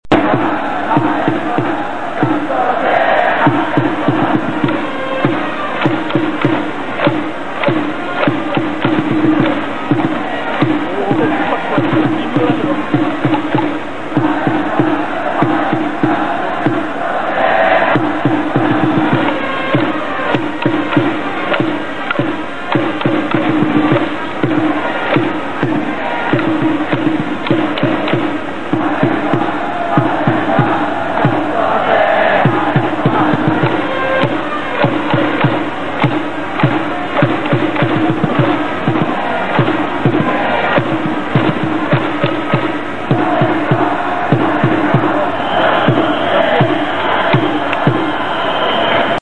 〜選手応援歌〜